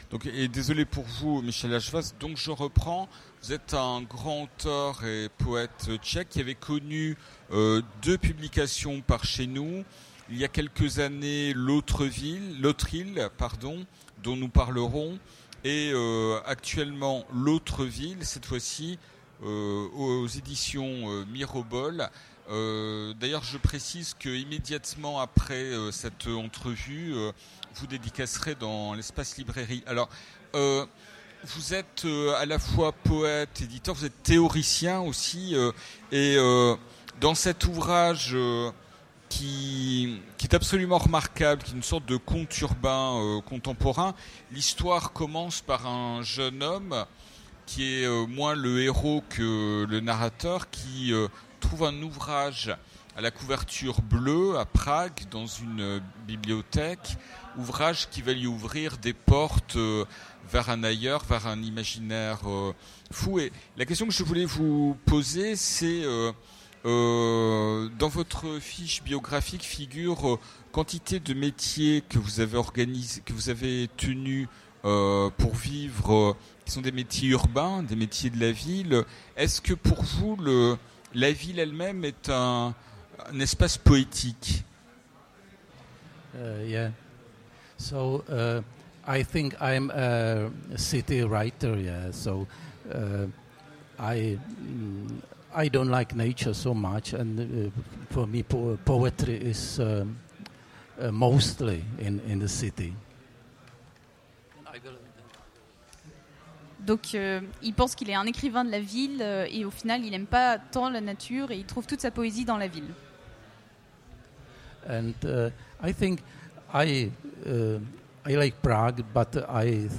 Utopiales 2015 : Rencontre avec Michal Ajvaz
- le 31/10/2017 Partager Commenter Utopiales 2015 : Rencontre avec Michal Ajvaz Télécharger le MP3 à lire aussi Michal Ajvaz Genres / Mots-clés Rencontre avec un auteur Conférence Partager cet article